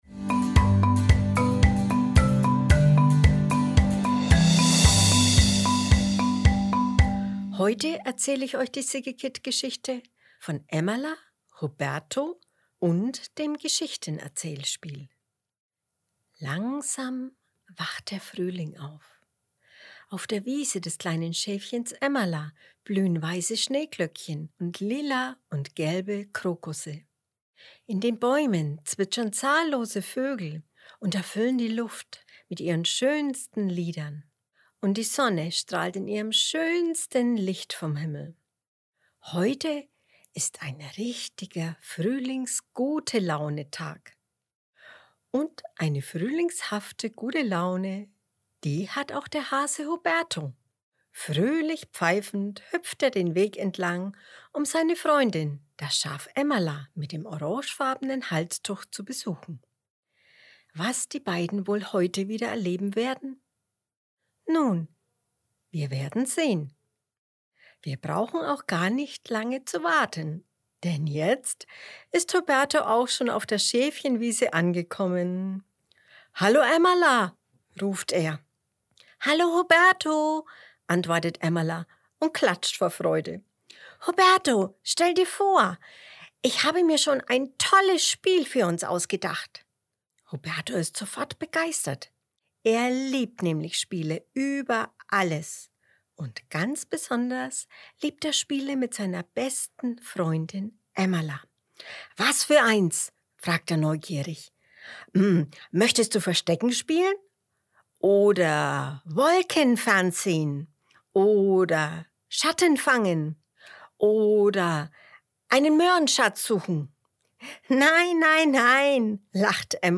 März 2022 Kinderblog Frühling, Jahreszeiten, Vorlesegeschichten, Emmala & Huberto Was für ein wunderschöner Frühlingstag!